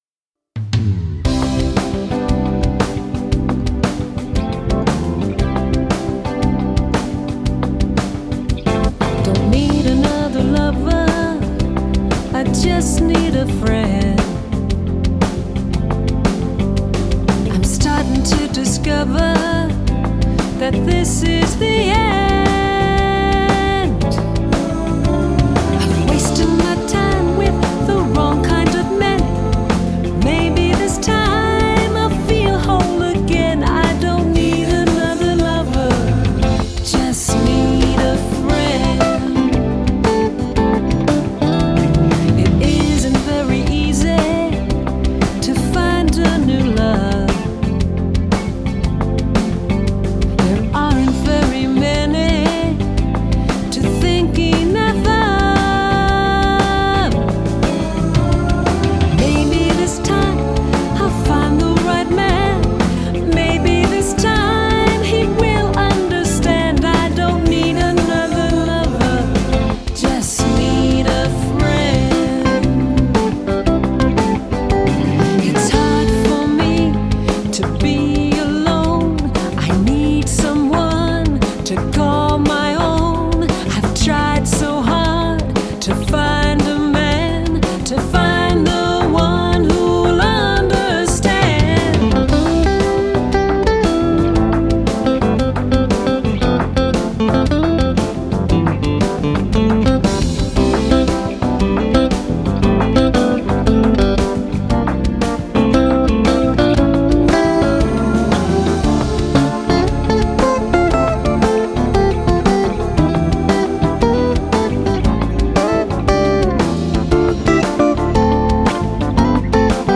Genre: Jazz/Pop Vocals